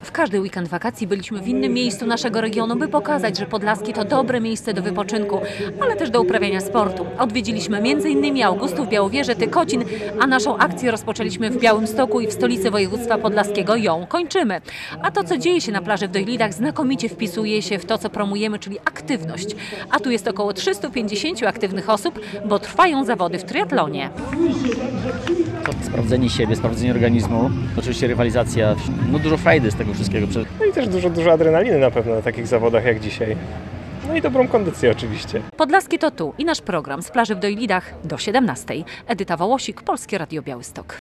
Finał naszej wakacyjnej akcji "Podlaskie to tu" - jesteśmy na plaży w Dojlidach | Pobierz plik.